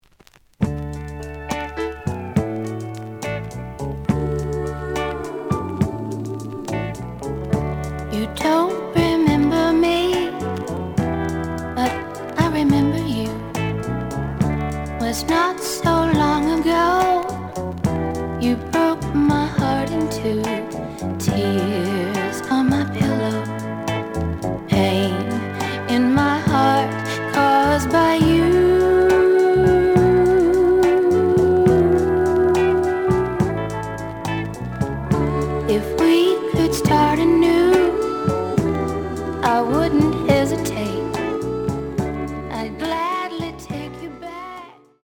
試聴は実際のレコードから録音しています。
●Format: 7 inch
●Genre: Rhythm And Blues / Rock 'n' Roll